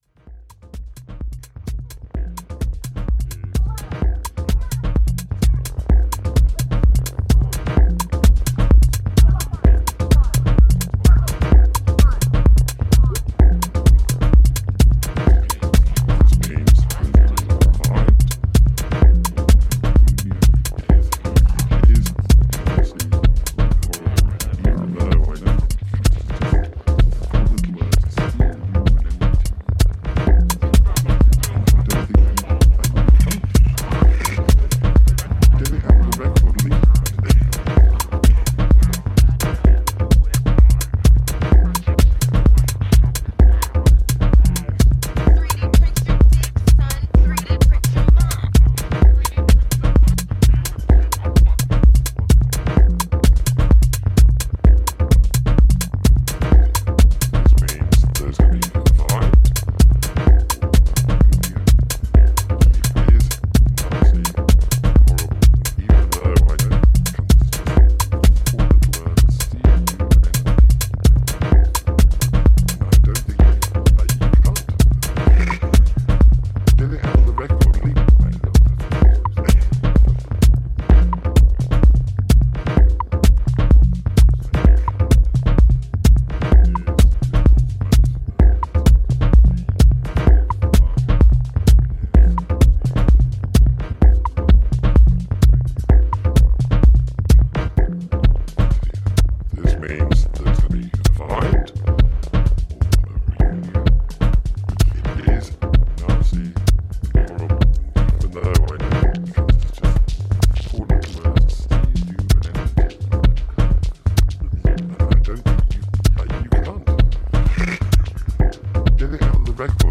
a modern take on Chicago House with a punk rock attitude